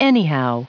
Prononciation du mot anyhow en anglais (fichier audio)
Prononciation du mot : anyhow